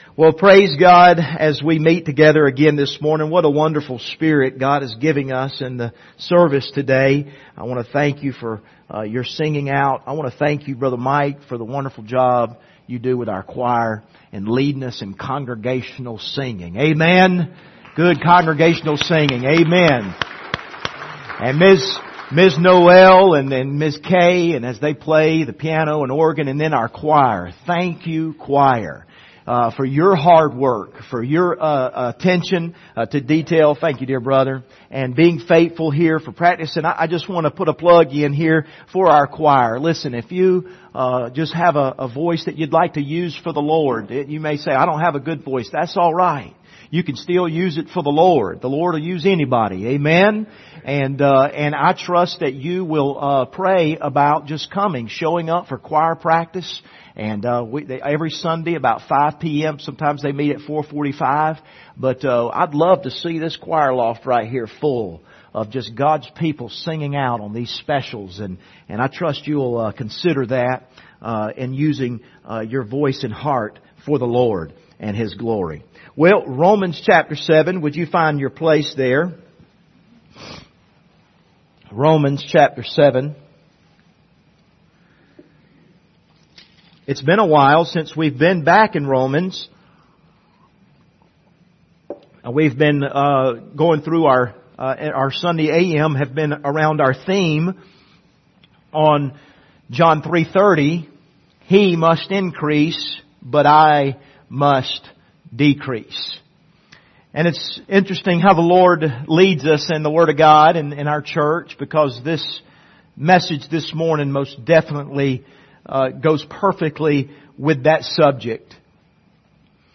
Passage: Romans 7:14-8:1 Service Type: Sunday Morning